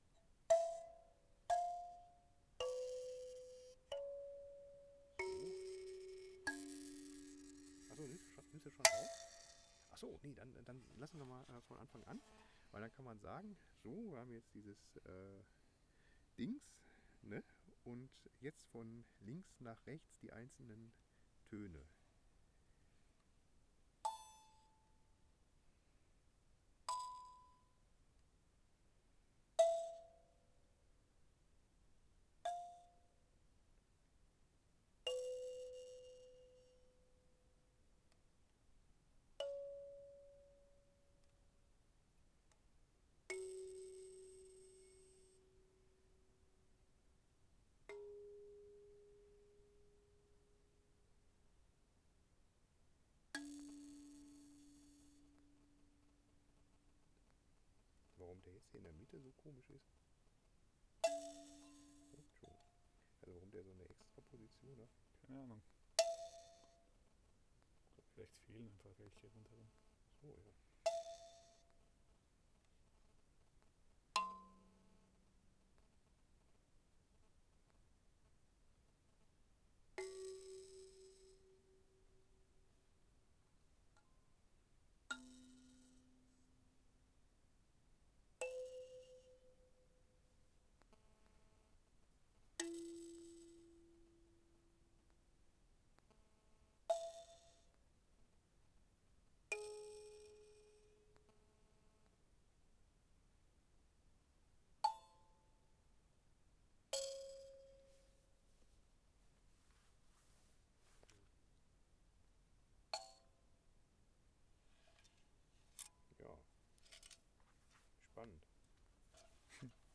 Reflexionsarmer Raum des Musikwissenschaftlichen Instituts der Universität Wien
Aufnahme der Lukeme im Abstand von 30 cm
Jeden Ton einmal spielen und vollständig ausklingen lassen.